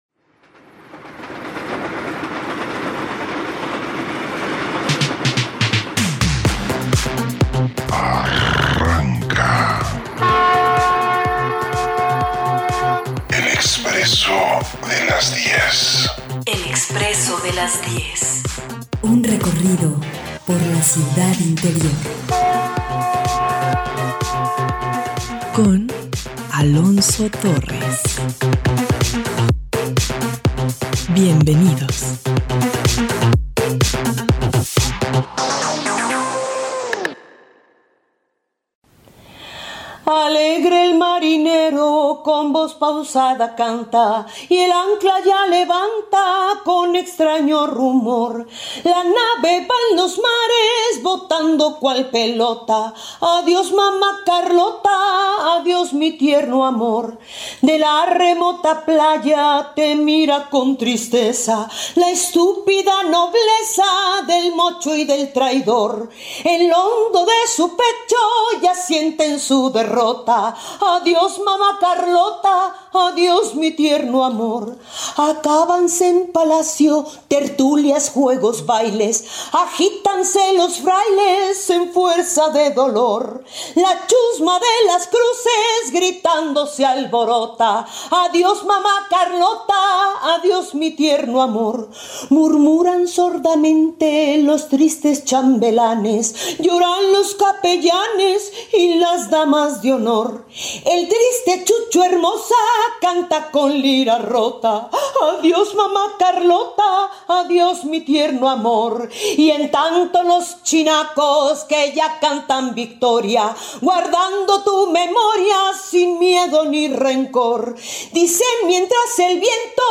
Una conversación